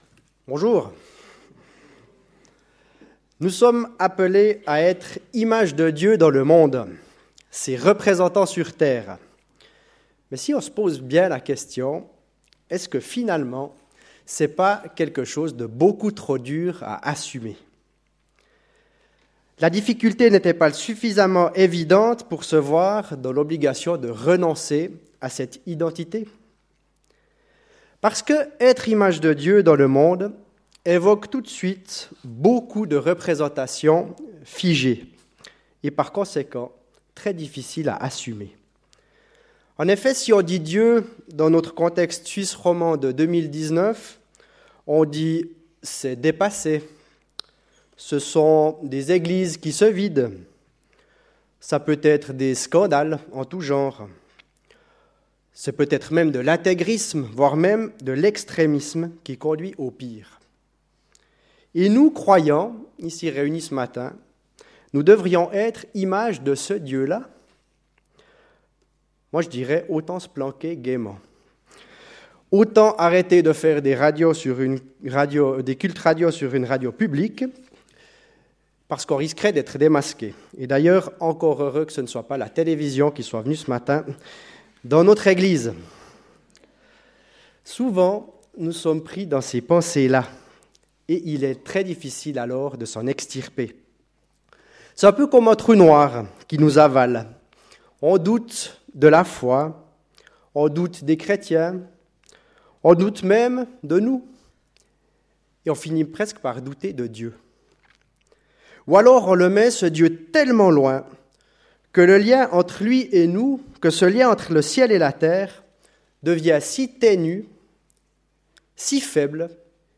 Culte du 27 janvier 2019 « Etre image de Dieu » 3/4 Trop dur à assumer ?